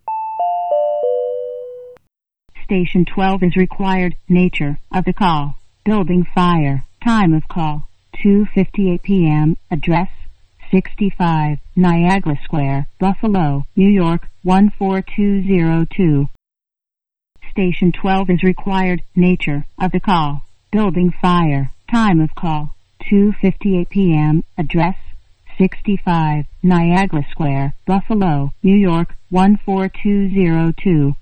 These voice alerts can be broadcast over site speakers or transmitted through connected radios to ensure immediate awareness.
FIRE-STATION-SAMPLE.wav